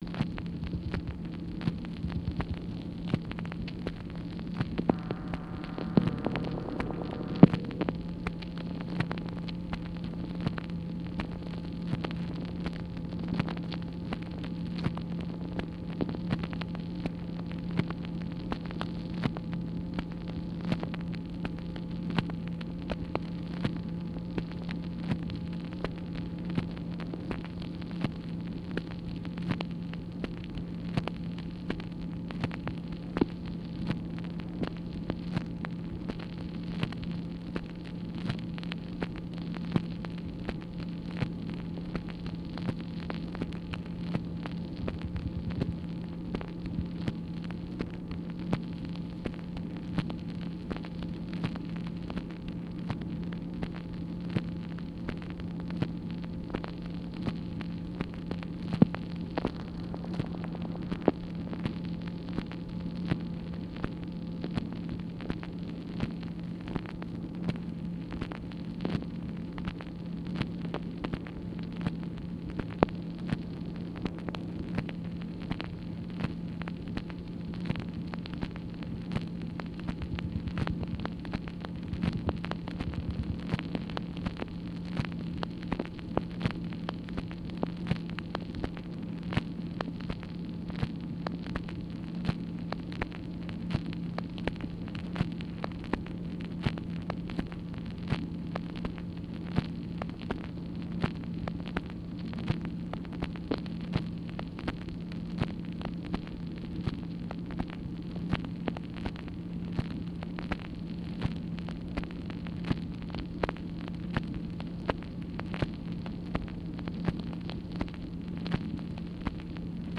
MACHINE NOISE, March 9, 1964